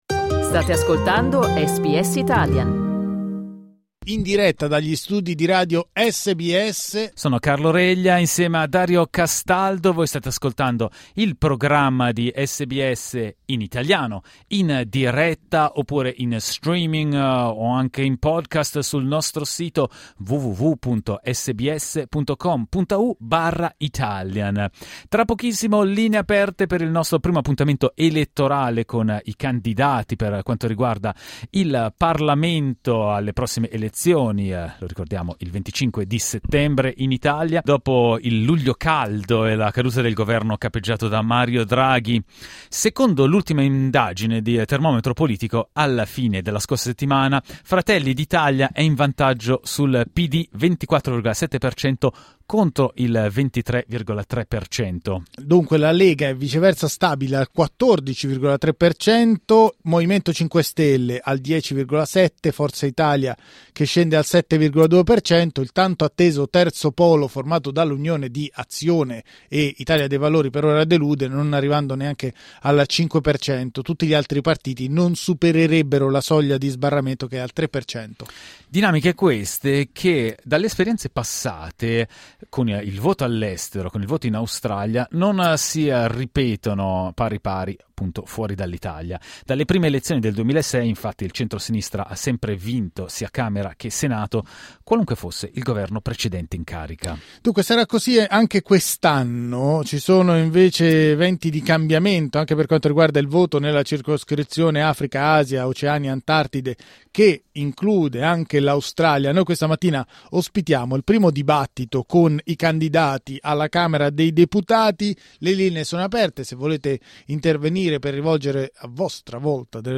SBS Italian ha invitato in diretta i candidati alla Camera per presentarsi e dibattere le loro politiche.